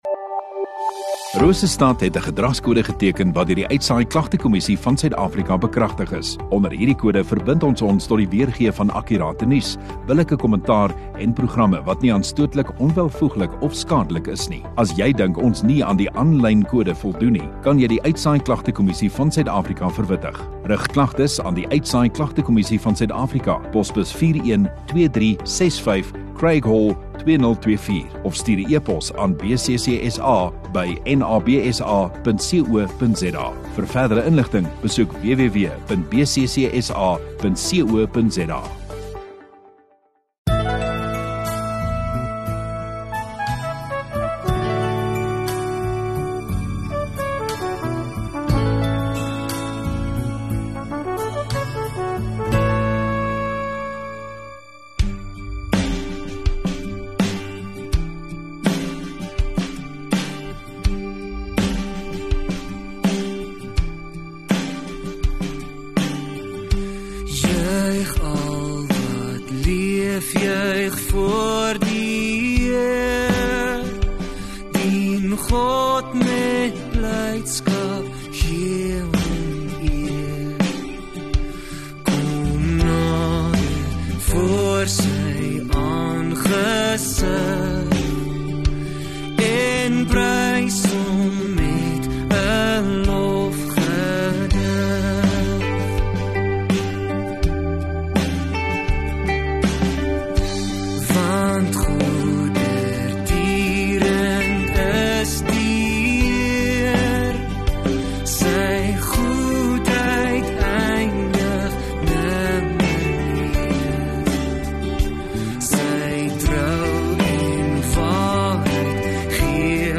5 May Sondagaand Erediens -